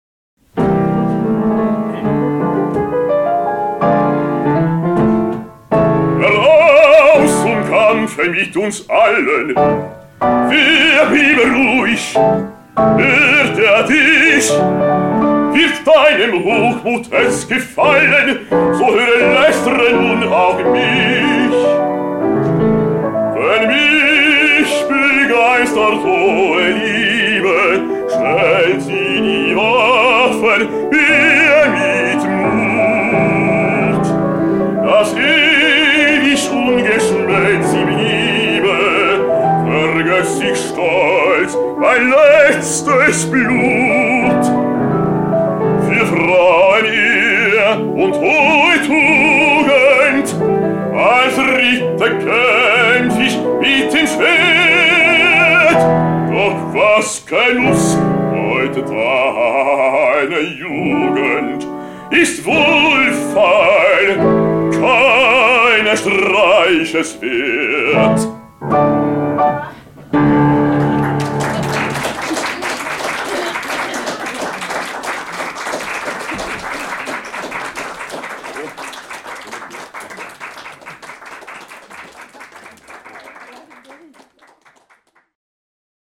Imponerande djupa toner
live från medlemsträffen den 26 april 2014.